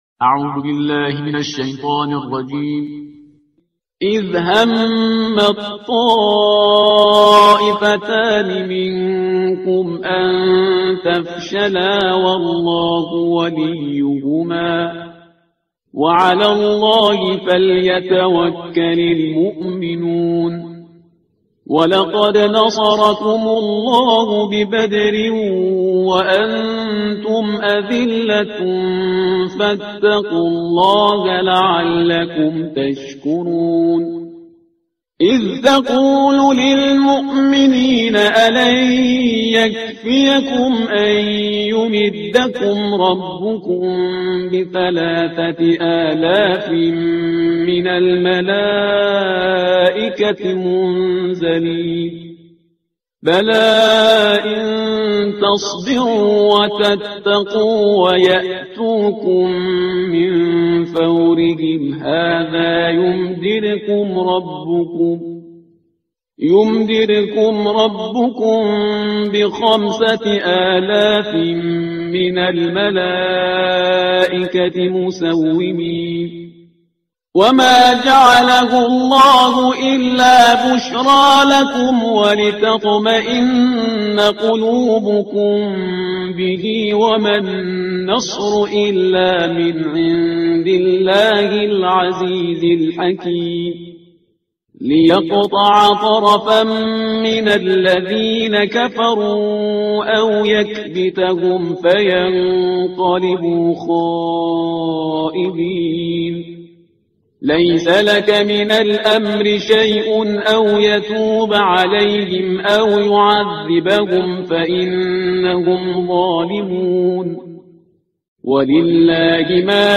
ترتیل صفحه 66 قرآن با صدای شهریار پرهیزگار